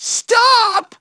synthetic-wakewords
ovos-tts-plugin-deepponies_Teddie_en.wav